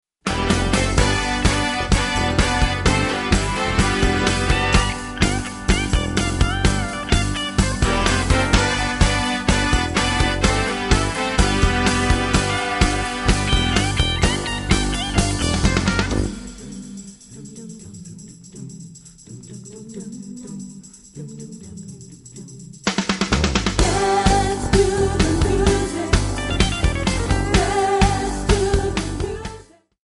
Dm
MPEG 1 Layer 3 (Stereo)
Backing track Karaoke
Pop, Rock, 1970s